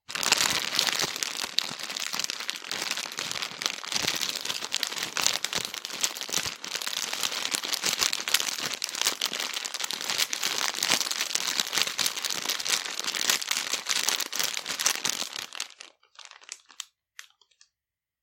塑料紧缩 长
描述：长时间塑料嘎吱嘎吱。
Tag: 塑料 SFX 效果 紧缩 拟音